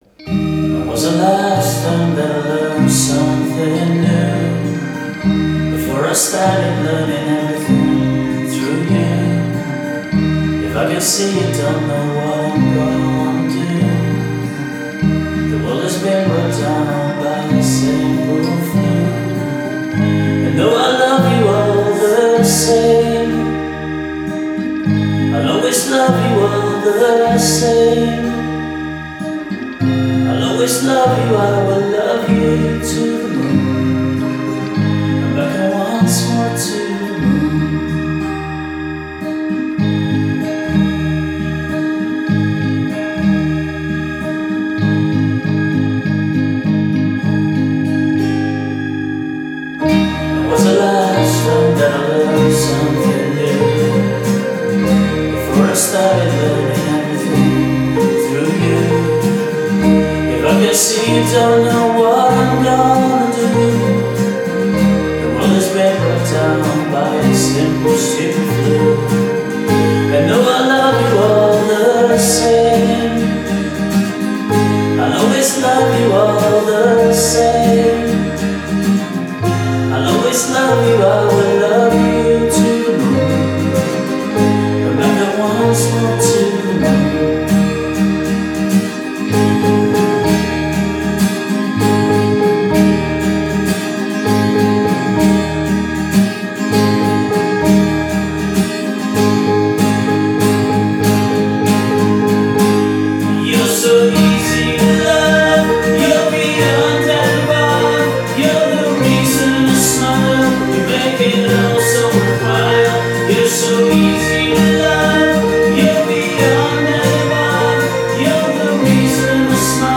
vocals, guitars, bass, keyboards
additional vocals